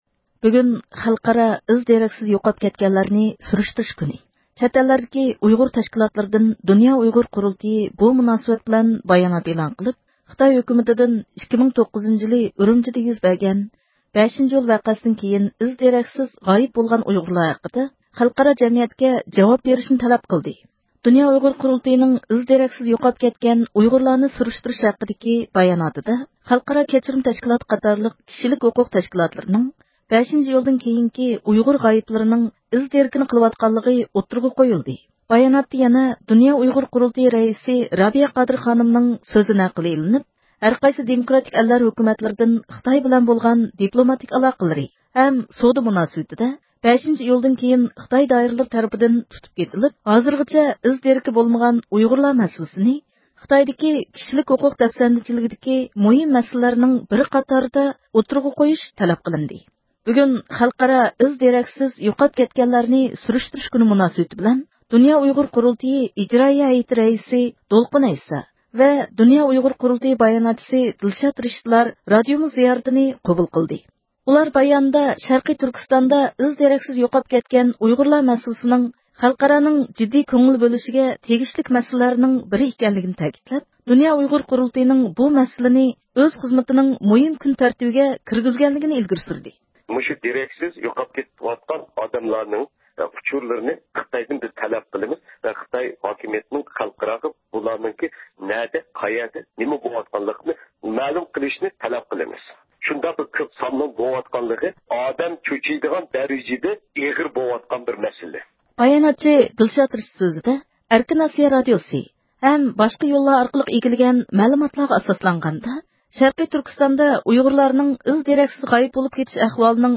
دۇنيا ئۇيغۇر قۇرۇلتىيىنىڭ ئىجرائىيە ھەيئىتى رەئىسى دولقۇن ئەيسا دۇنيا ئۇيغۇر قۇرۇلتىيىنىڭ بۇ ھەقتە ئېلىپ بېرىۋاتقان كونكرېت خىزمەتلىرى ھەققىدە توختالدى.